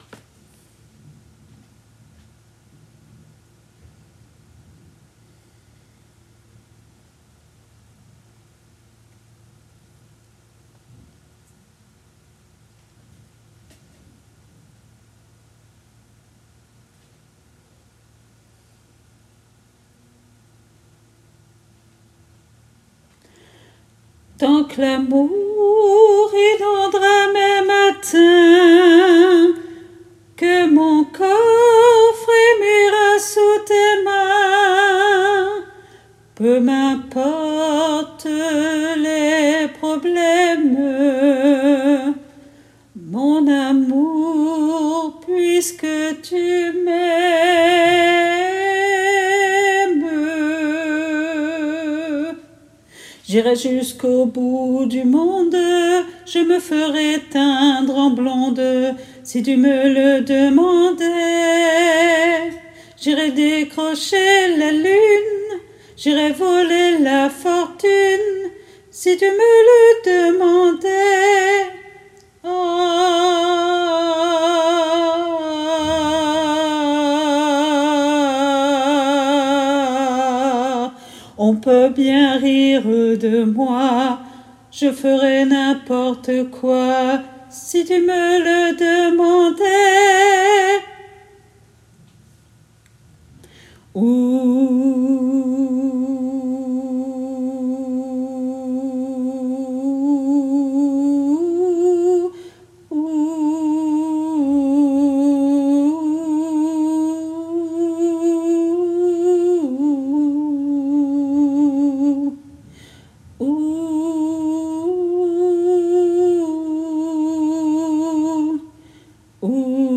MP3 versions chantées
Alto